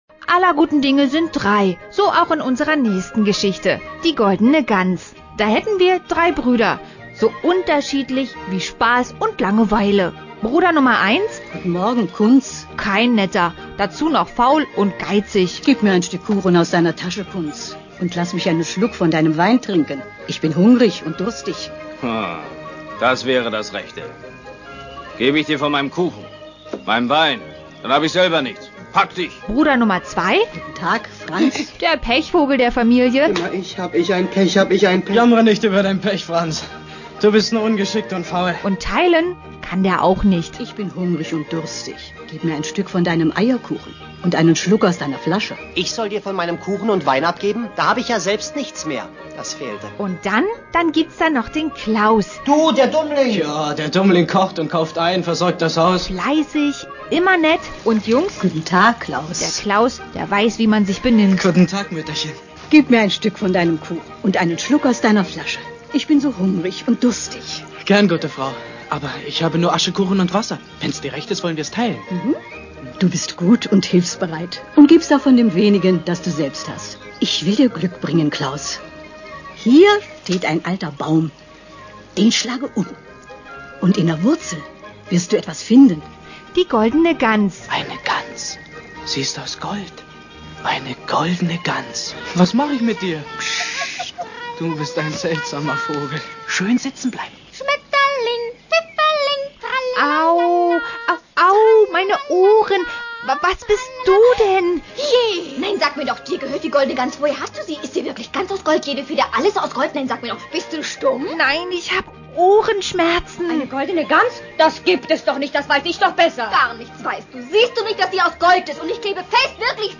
Audio-Trailer